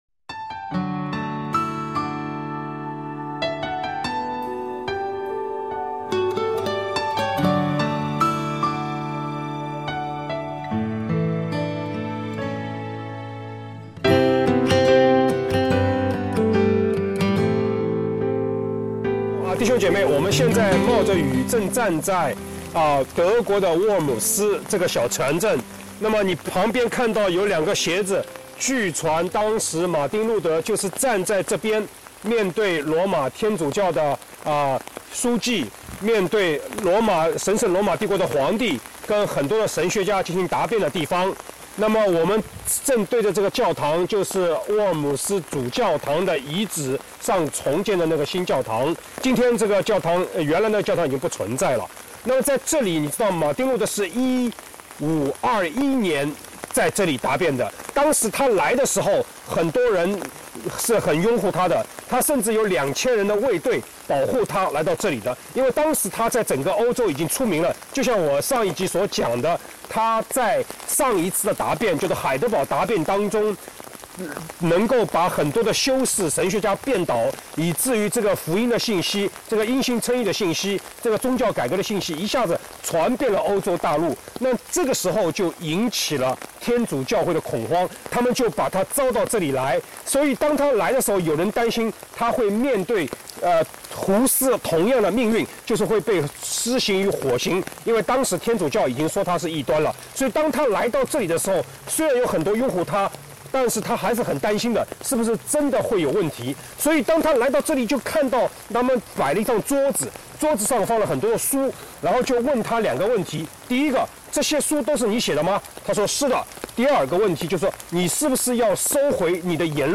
德国沃尔姆斯小镇